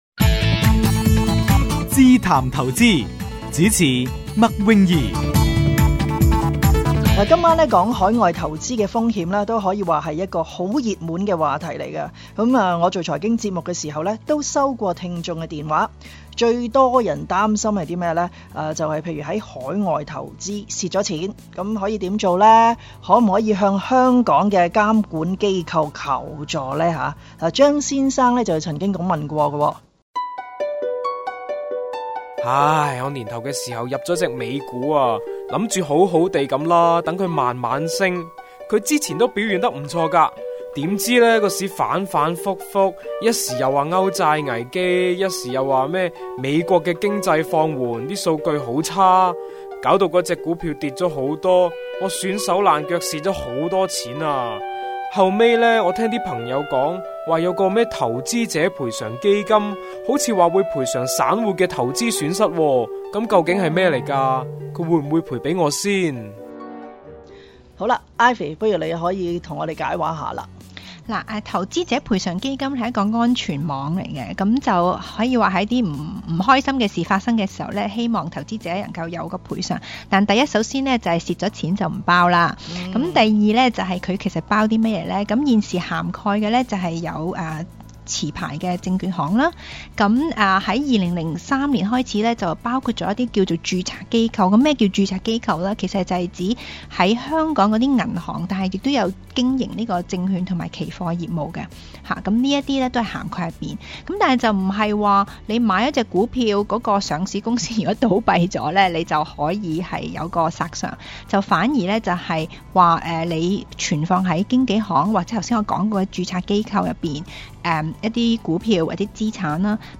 每輯專訪會邀請一名市場專業人士和一名證監會的代表探討熱門的投資話題。